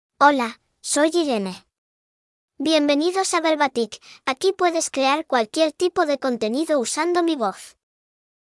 FemaleSpanish (Spain)
Voice sample
Listen to Irene's female Spanish voice.
Female
Irene delivers clear pronunciation with authentic Spain Spanish intonation, making your content sound professionally produced.